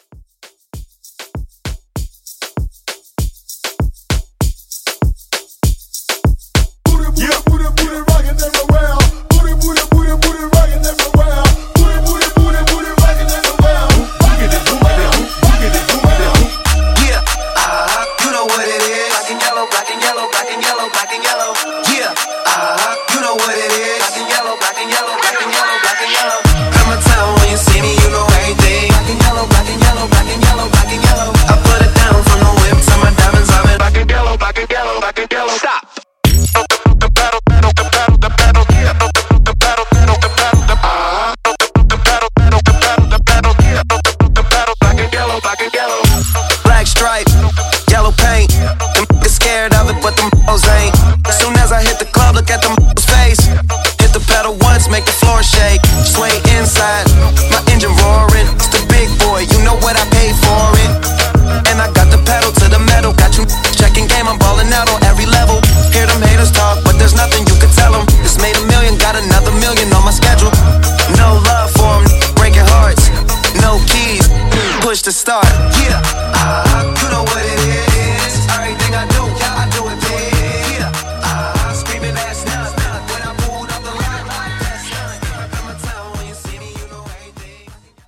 Genre: BOOTLEG Version: Clean BPM: 98 Time